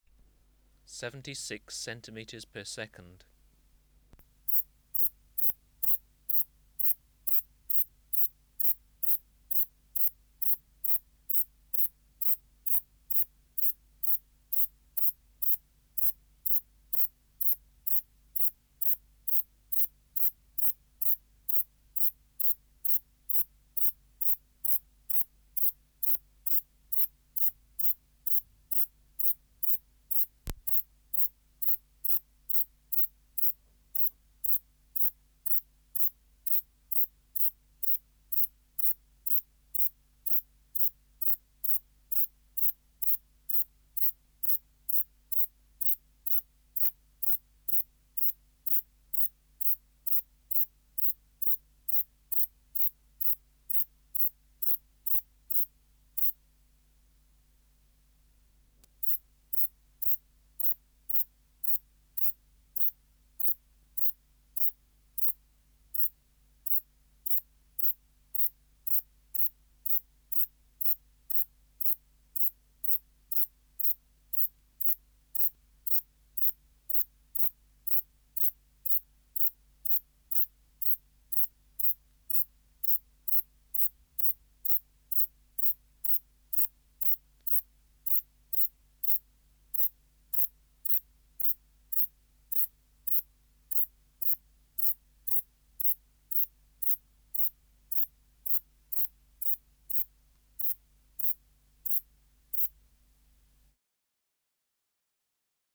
398:12 Ephippiger ephippiger (404b) | BioAcoustica
Recording Location: BMNH Acoustic Laboratory
Reference Signal: 1 kHz for 10s
Substrate/Cage: Large recording cage
Microphone & Power Supply: Sennheiser MKH 405 Distance from Subject (cm): 20